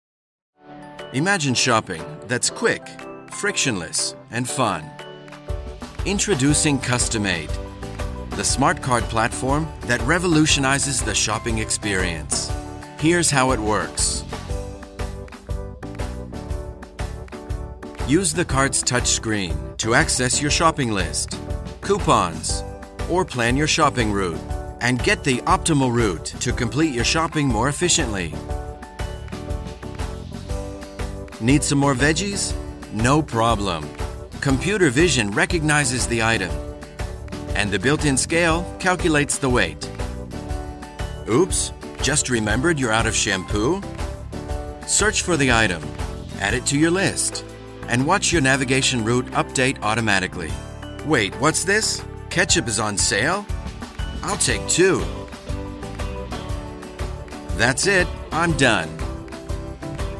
Giọng nam thu âm tiếng Anh (bản ngữ)
Giọng nam người Mĩ
Explainer video
Explainer-Video-forwp.mp3